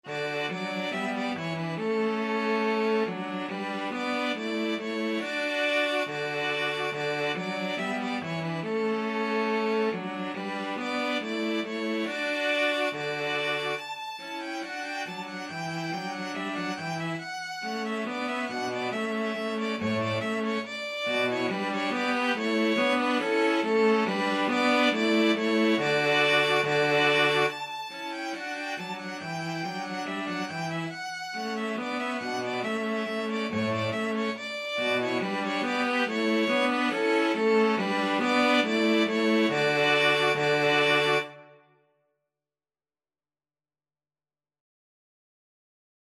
Free Sheet music for String trio
ViolinViolaCello
D major (Sounding Pitch) (View more D major Music for String trio )
2/2 (View more 2/2 Music)
String trio  (View more Easy String trio Music)
Traditional (View more Traditional String trio Music)